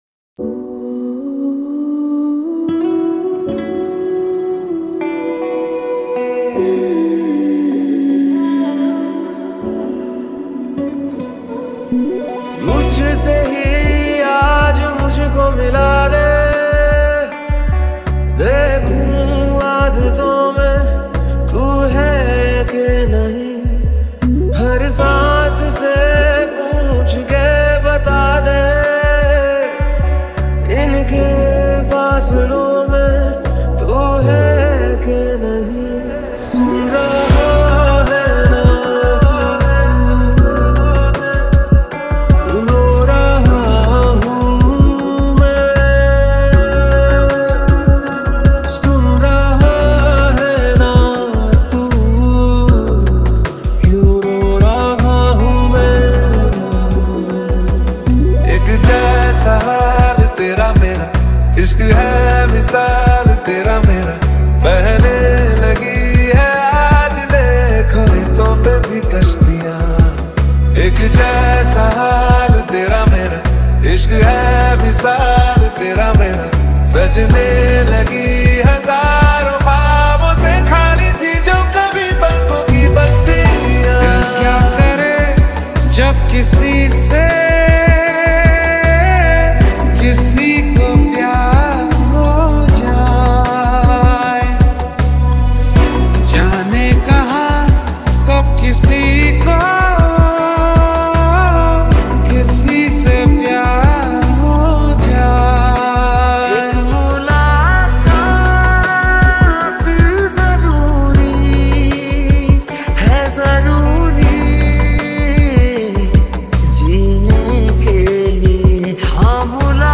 Category New Dj Mp3 Songs 2025 Singer(s